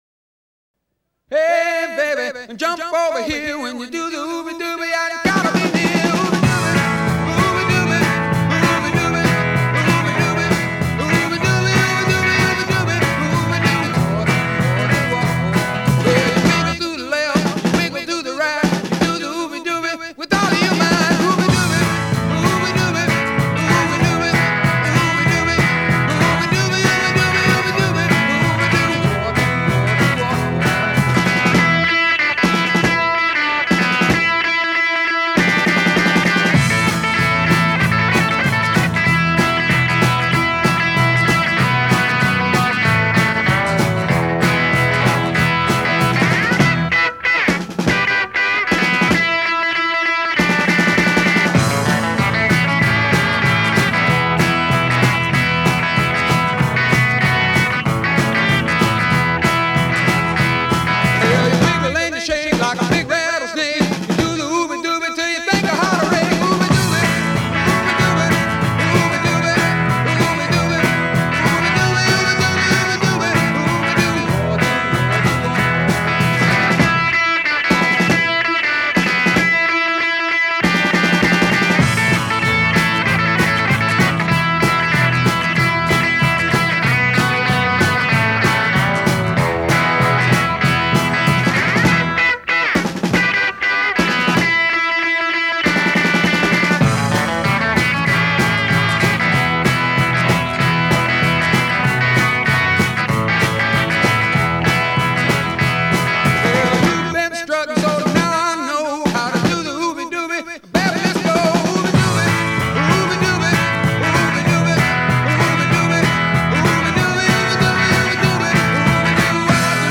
Файл в обменнике2 Myзыкa->Зарубежный рок
Жанры: Roots rock, Свомп-поп, Кантри-рок